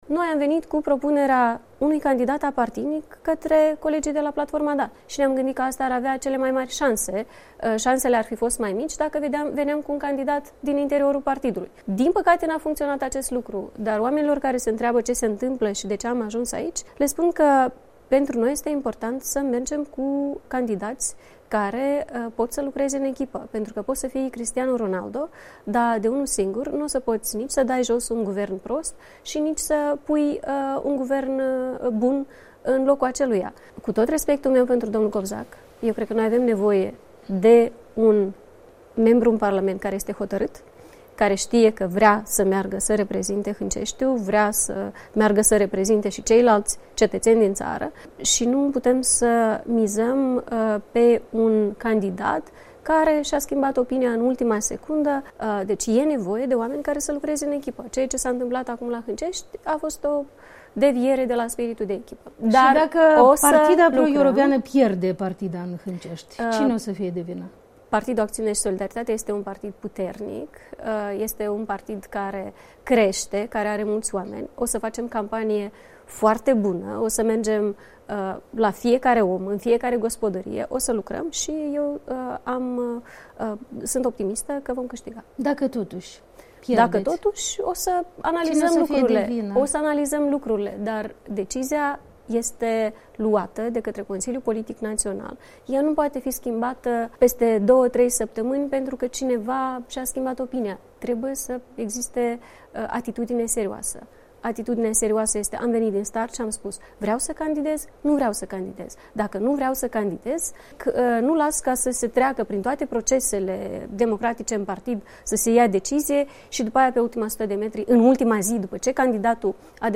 După ce nu au reuşit să se pună de acord asupra unui candidat comun la alegerile din Hânceşti, vor reuşi oare cele două componente ale Blocului ACUM – PAS și PPDA –să se înţeleagă mai bine cel puţin în perspectiva alegerilor prezidenţiale? Într-un interviu la Europa Liberă, lidera PAS, Maia...